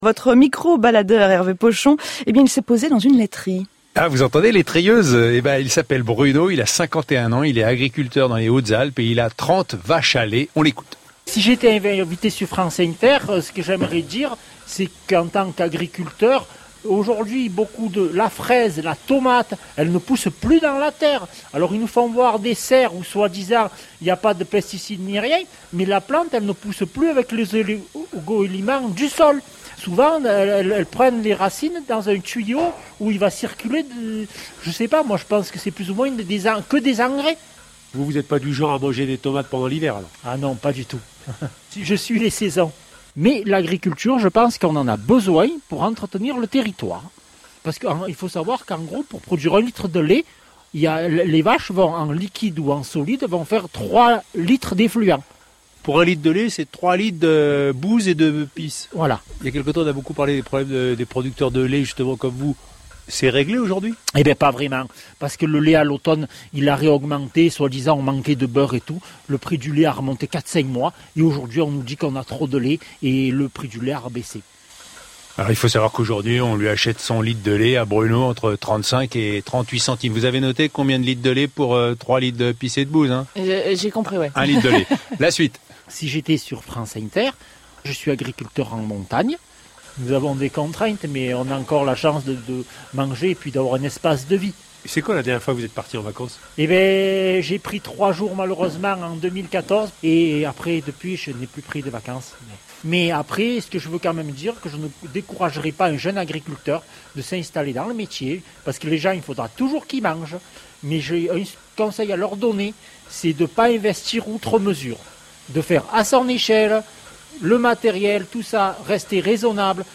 Ce matin à la radio, pendant quelques minutes, on était dans le département des Hautes-Alpes, auprès d’un agriculteur qui parlait de fraises et de tomates, de ses vaches, de son travail et de ses idées sur son métier. Accent du sud-est, pour dire en filigrane les difficultés de ceux qui ne sont pas dans l’agriculture intensive et qui pourtant nous nourrissent et ne veulent plus massacrer le milieu dans lequel nous vivons.
eleveur-dans-les-hautes-alpes.mp3